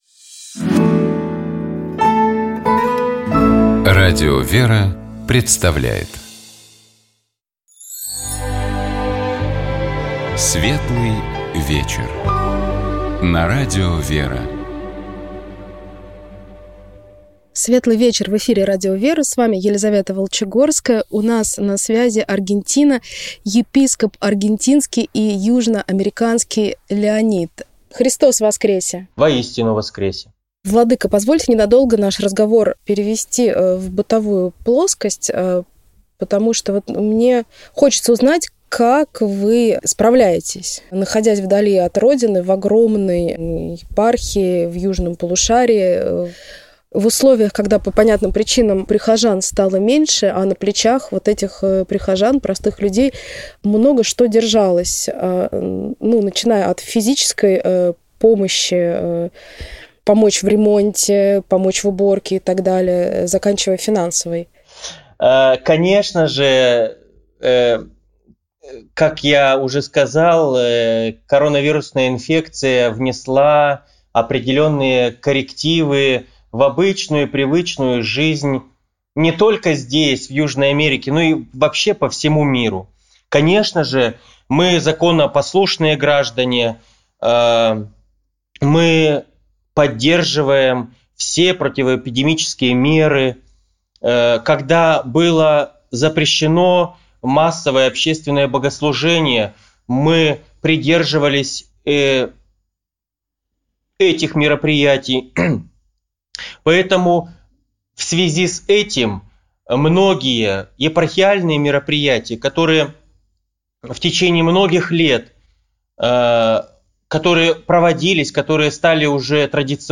Нашим собеседником был епископ Аргентинский и Южноамериканский Леонид.